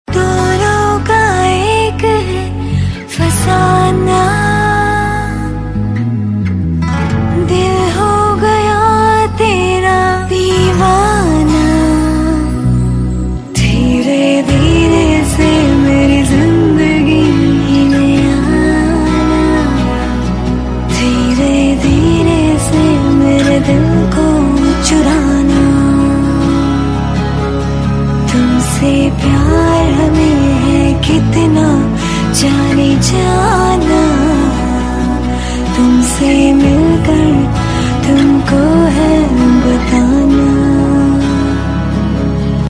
TV Serial Tone